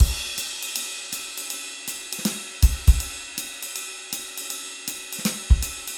Jazz 4
Swing / 160 / 4 mes
JAZZ 1- 160.mp3